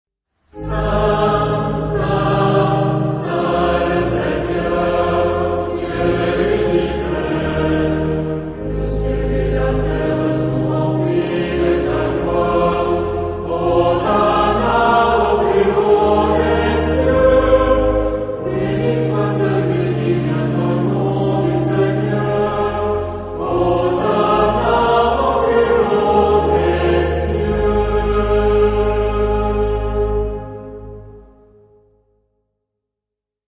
Genre-Style-Forme : Messe
Caractère de la pièce : solennel
Type de choeur :  (1 voix unisson )
Instruments : Orgue (1)
Tonalité : la majeur